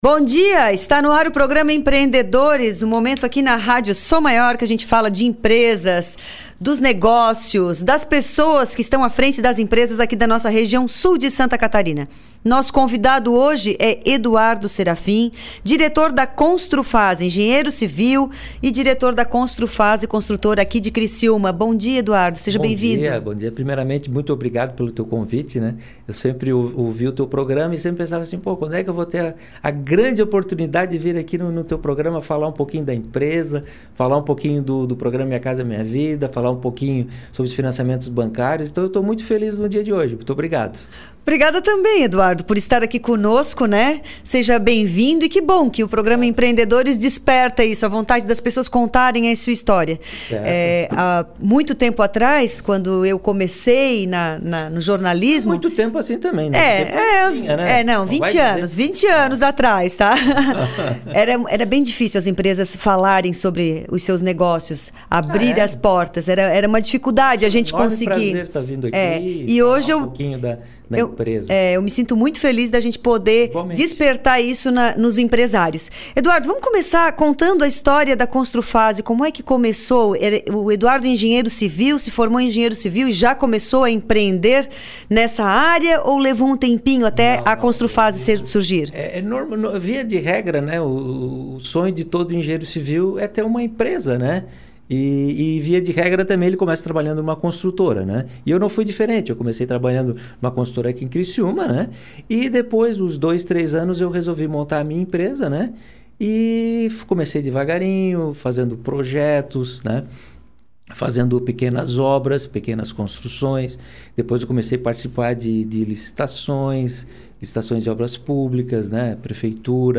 O Programa Empreendedores é veiculado originalmente na Rádio Som Maior.